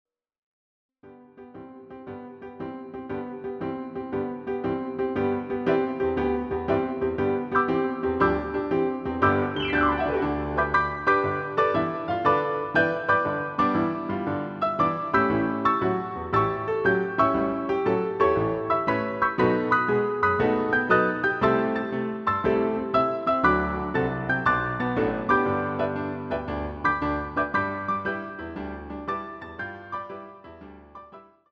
using the stereo sampled sound of a Yamaha Grand Piano.